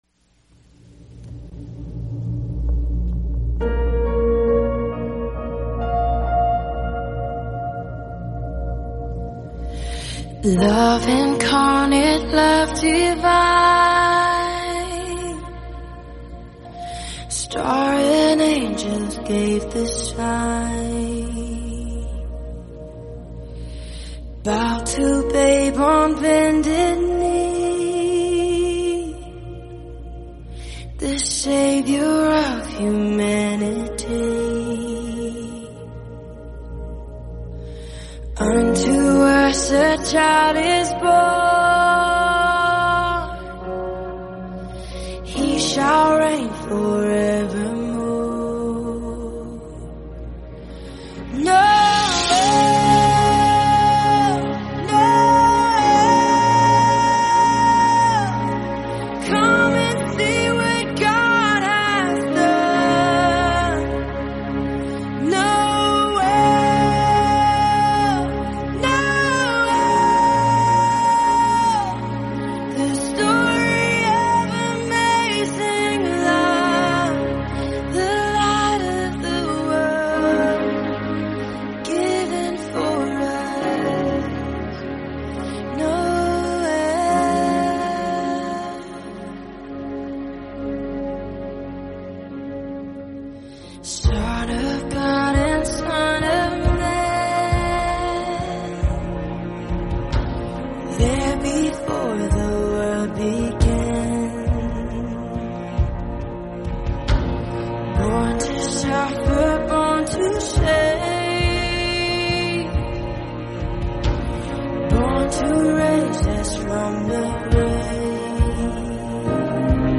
“Noel” performed by Lauren Ashley Daigle, composed by Chris Tomlin.